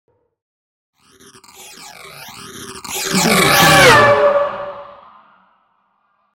Sci fi vehicle pass by
Sound Effects
futuristic
pass by